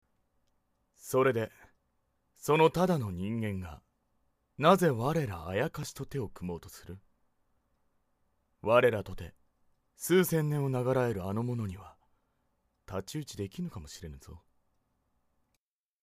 【サンプルボイス】